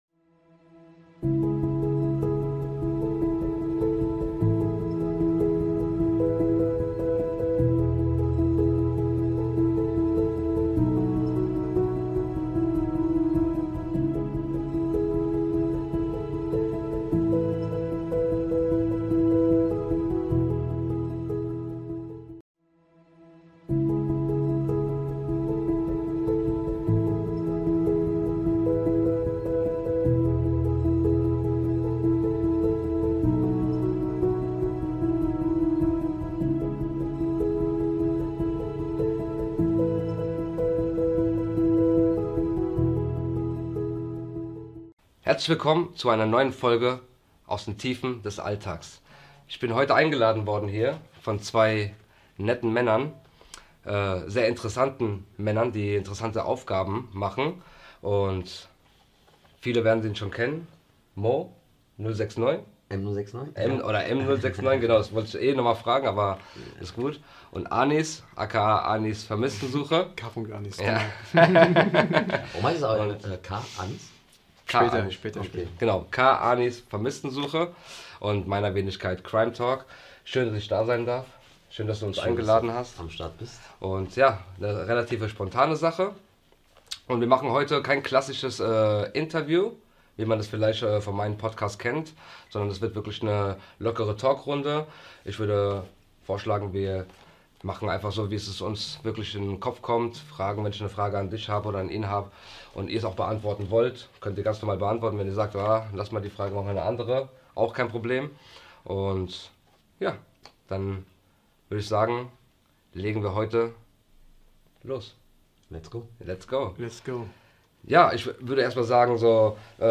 Ein ehrliches Gespräch über Menschen, die oft niemand mehr sieht – und darüber, warum Wegsehen keine Option ist.